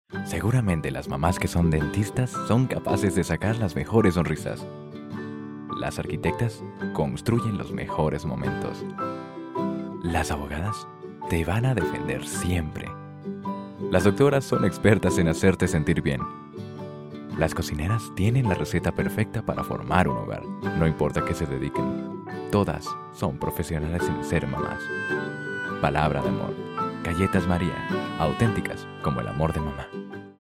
Spanish - Latin American Neutral, Smooth and professional for presentations. Multifaceted for talents in commercials. Professional for announcer. Warm for Narrations. Multifaceted since young male till midle elegant male for voice over. a lot of voices, a lot of characters. Comic, fun. Versatil
Sprechprobe: Industrie (Muttersprache):